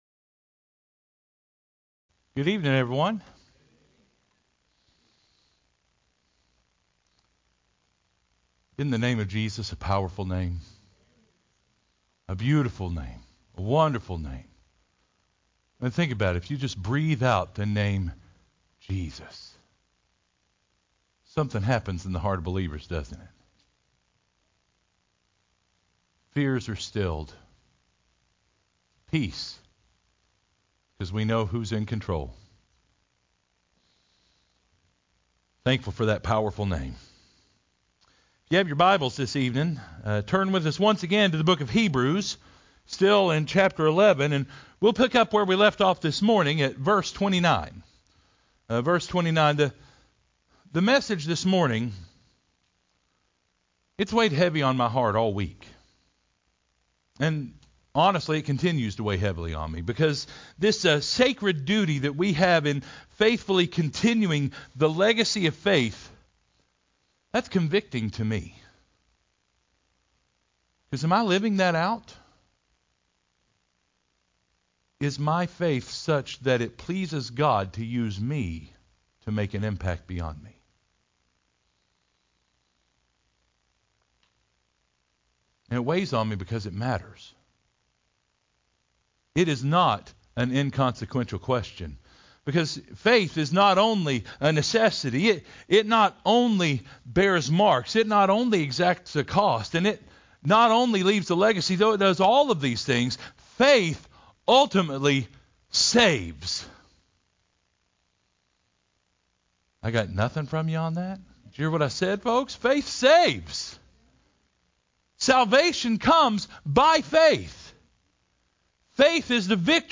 “Salvation By Faith” – First Baptist Church of Mossy Head